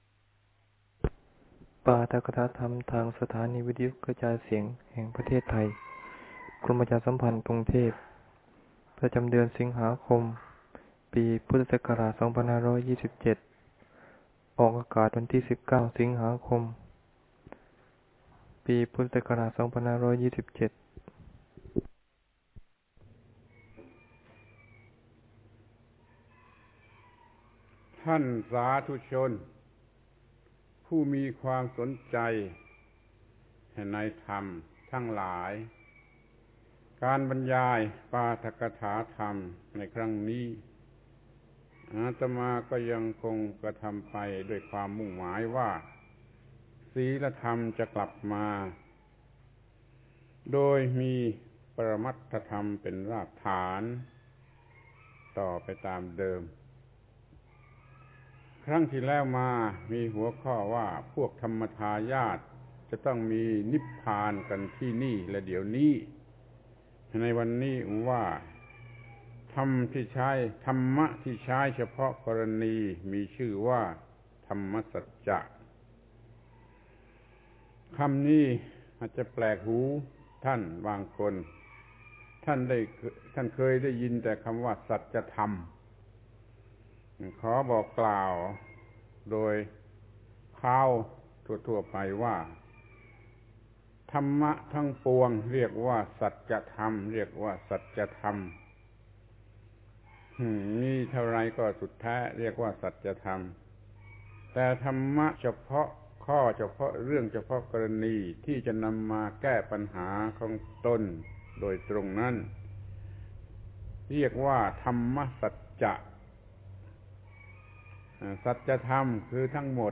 ปาฐกถาธรรมทางวิทยุฯ ปรมัตถธรรมกลับมา ครั้งที่ 36 ธรรมะที่ใช้เฉพาะกรณีมีชื่อว่าธรรมสัจจะ